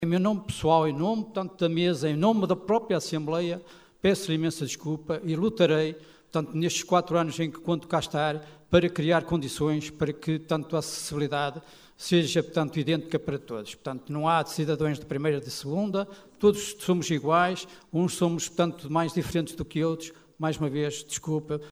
Excertos da Assembleia Municipal extraordinária de 24 de Novembro.